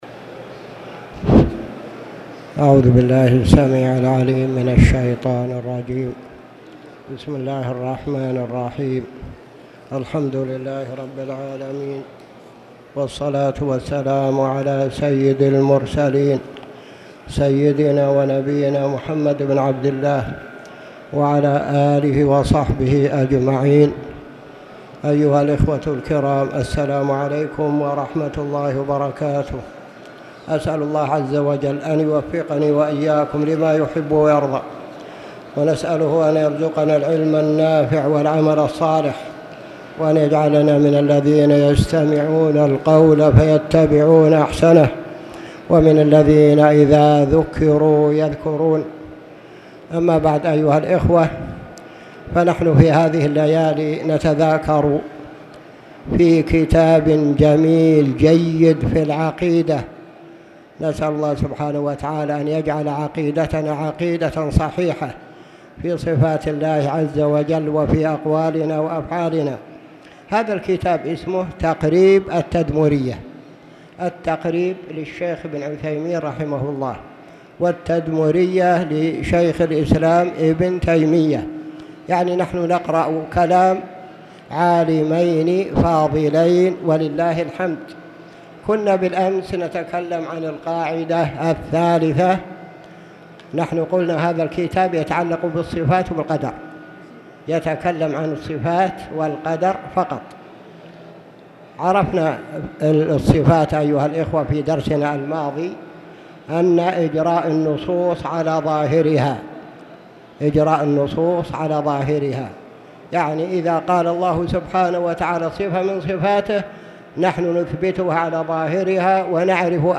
تاريخ النشر ٧ صفر ١٤٣٨ هـ المكان: المسجد الحرام الشيخ